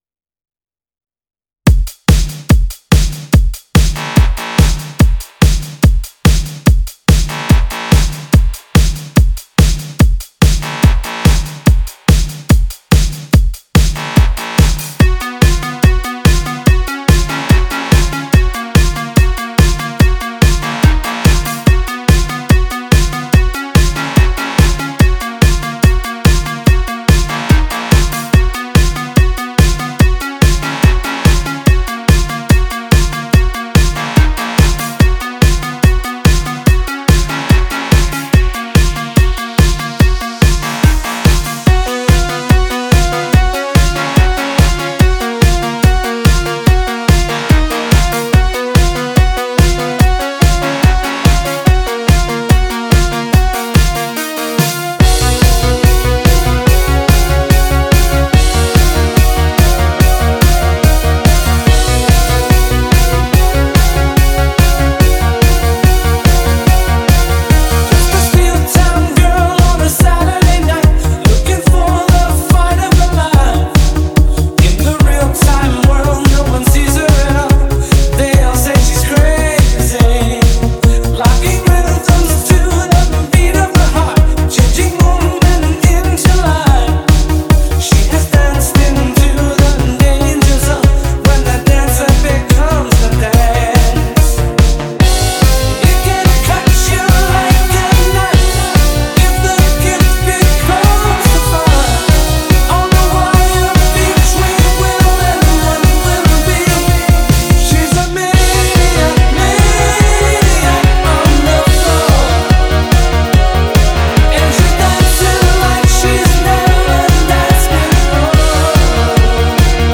• Genre Indie Dance, Pop